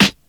• '00s Short Rap Steel Snare Drum Sample G Key 409.wav
Royality free snare tuned to the G note. Loudest frequency: 2300Hz
00s-short-rap-steel-snare-drum-sample-g-key-409-mmf.wav